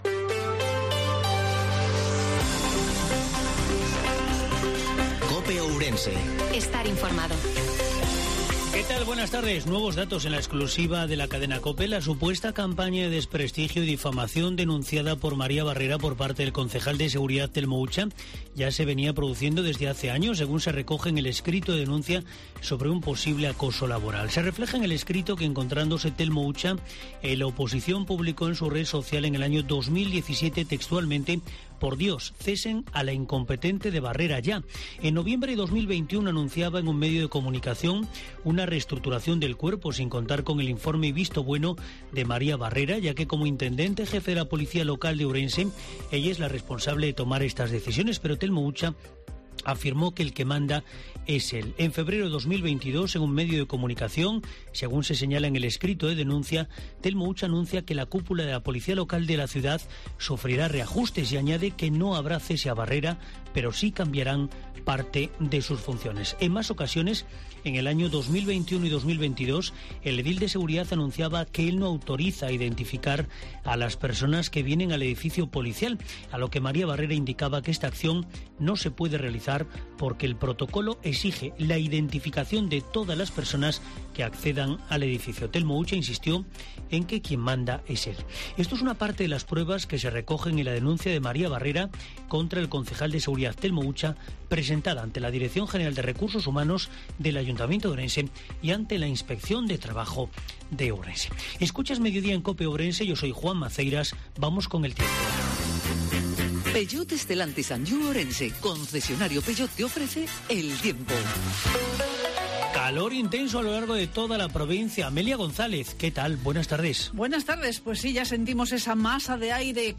INFORMATIVO MEDIODIA COPE OUIRENSE